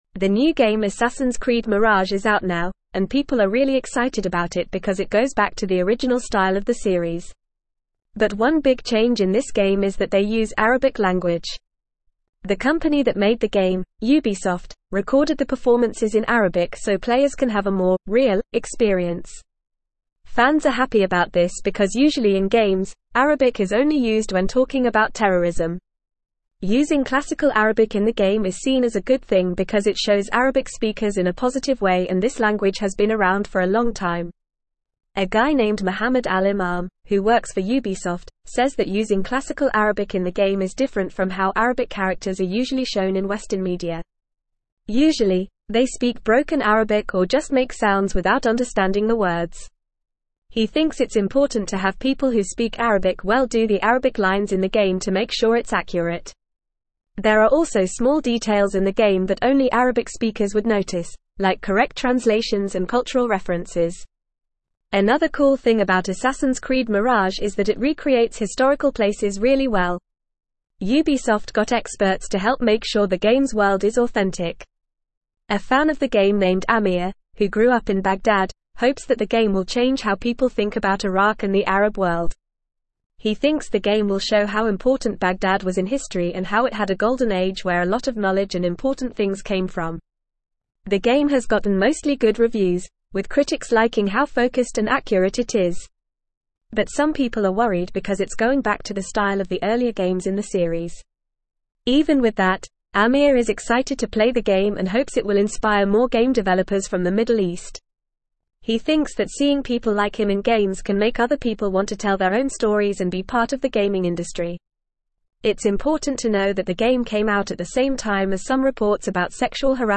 Fast
English-Newsroom-Upper-Intermediate-FAST-Reading-Assassins-Creed-Mirage-Authentic-Arabic-Representation-in-Gaming.mp3